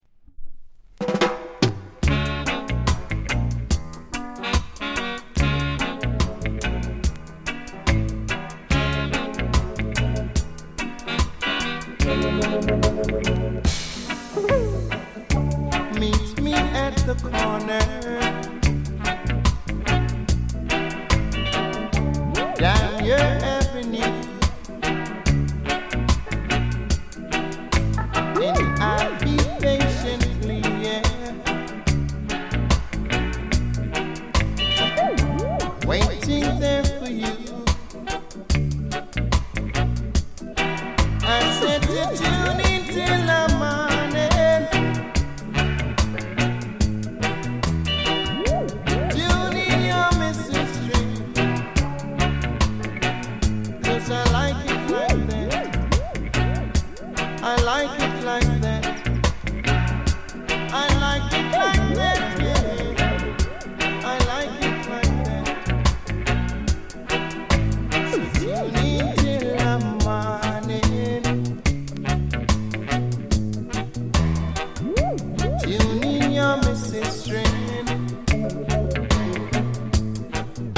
高音質
B面 音に影響ない 軽いセンターずれ。